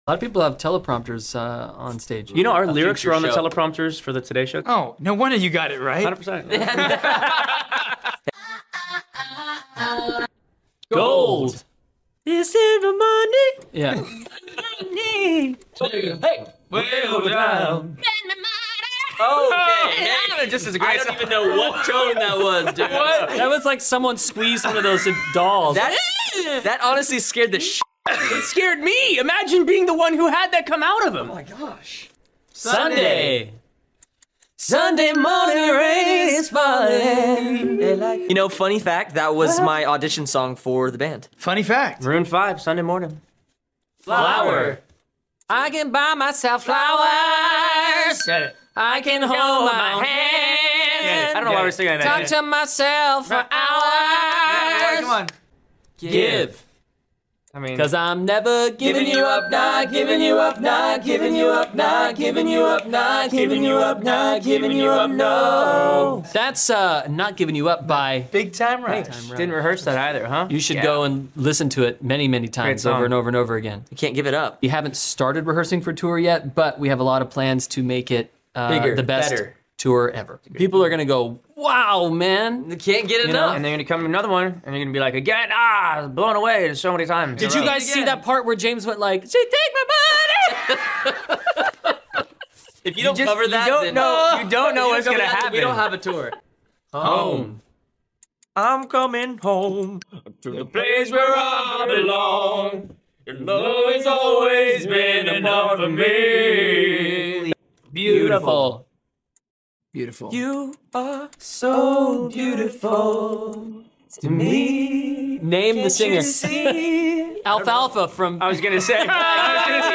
I edited another interview from sound effects free download